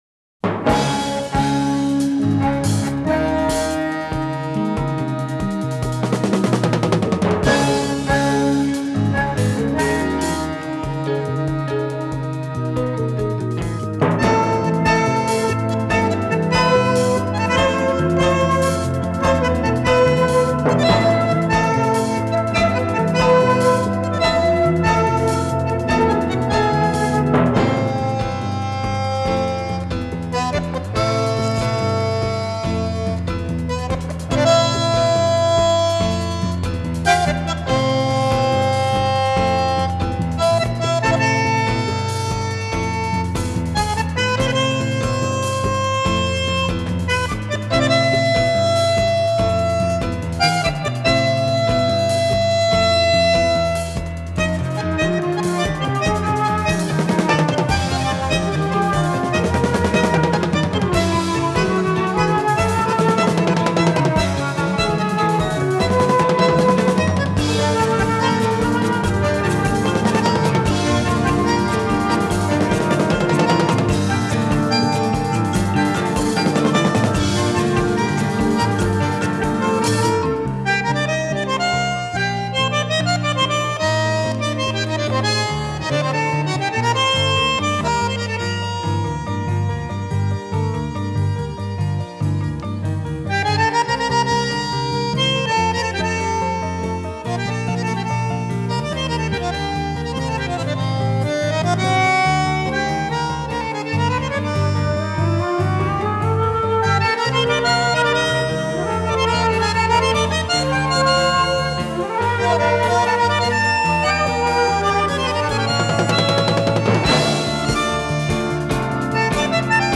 Танго
bandoneon
Recorded in Milan, Italyin May 1974